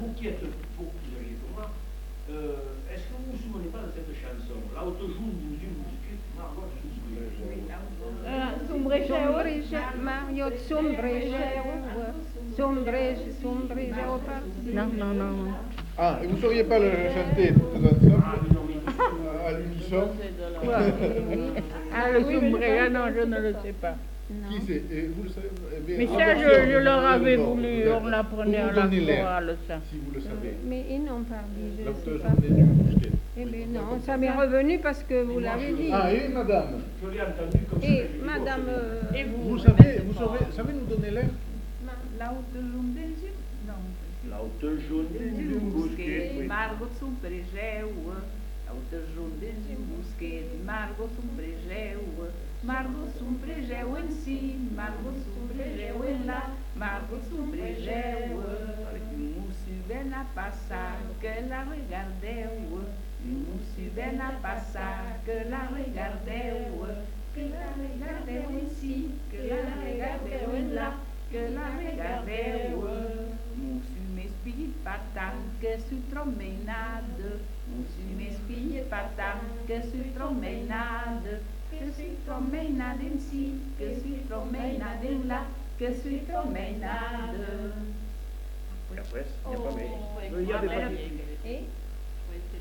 Lieu : Bazas
Genre : chant
Effectif : 2
Type de voix : voix de femme
Production du son : chanté
Description de l'item : fragment ; 3 c. ; refr.